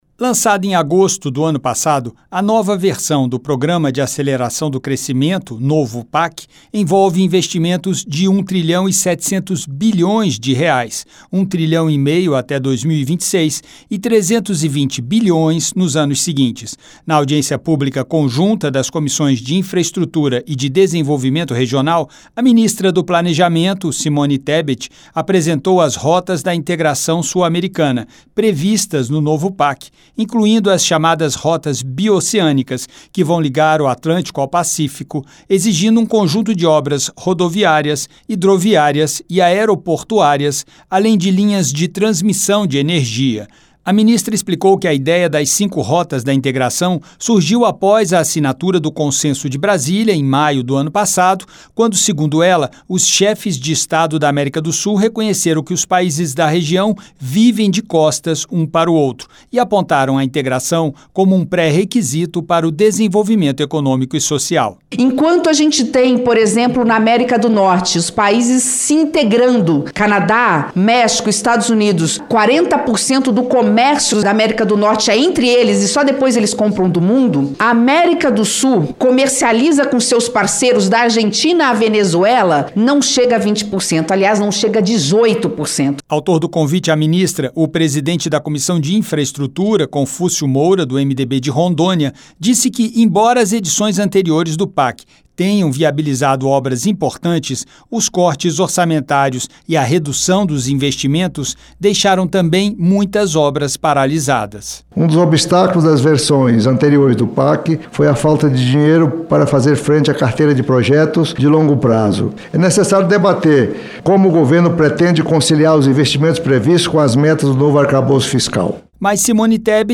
As comissões de Infraestrutura e de Desenvolvimento Regional receberam em audiência pública, nesta terça-feira (2), a ministra do Planejamento, Simone Tebet. Ela que apresentou as Rotas da Integração Sul-Americana, previstas no novo Programa de Aceleração do Crescimento (PAC) para ligar o Atlântico ao Pacífico com um conjunto de obras rodoviárias, hidroviárias e aeroportuárias.